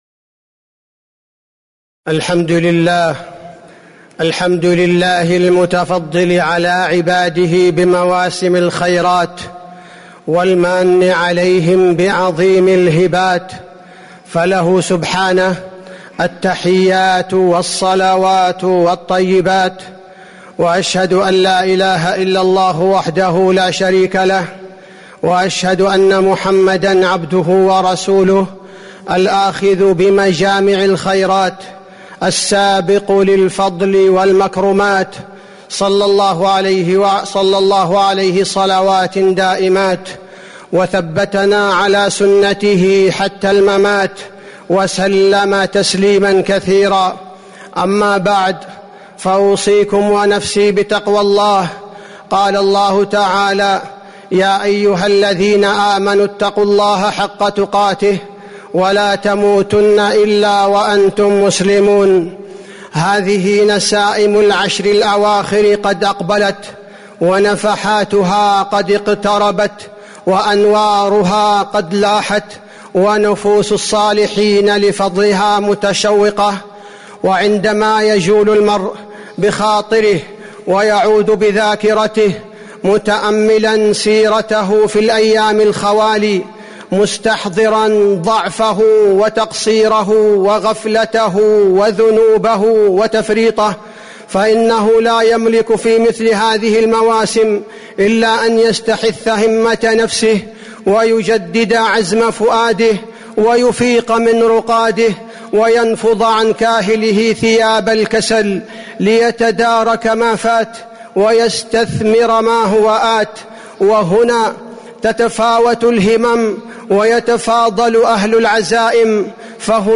تاريخ النشر ١٦ رمضان ١٤٤٤ هـ المكان: المسجد النبوي الشيخ: فضيلة الشيخ عبدالباري الثبيتي فضيلة الشيخ عبدالباري الثبيتي حال المؤمن في رمضان